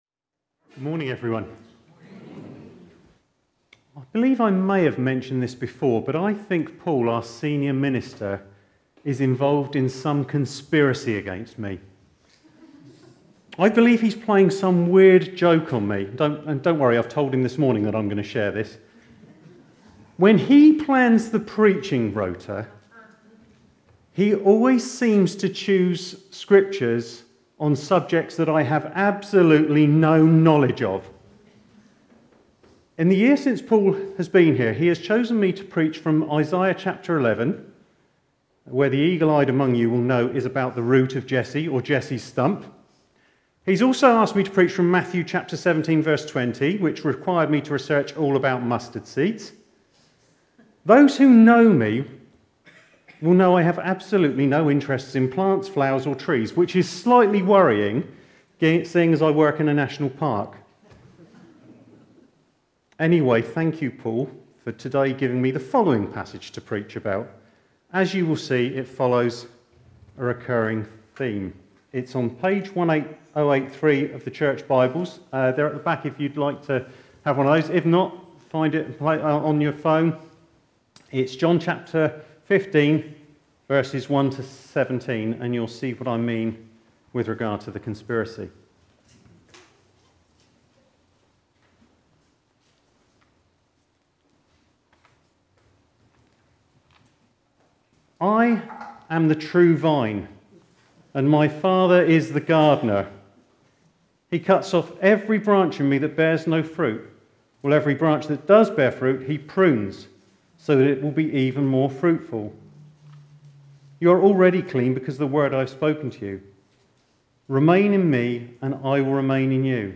Talks and Sermons - Thornhill Baptist Church